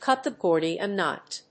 cút the Górdian knót
発音